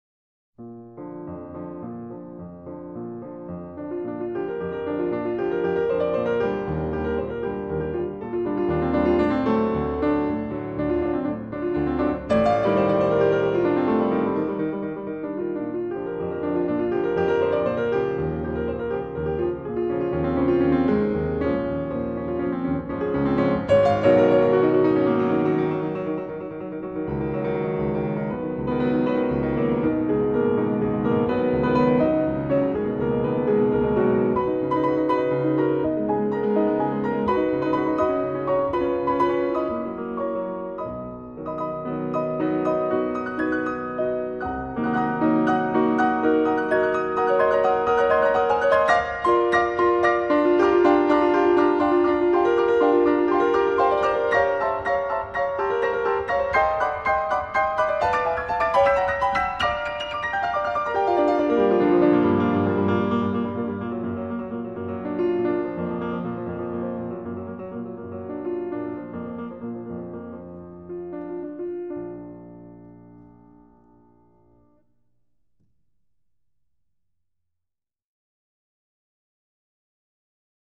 0198-钢琴名曲华尔兹.mp3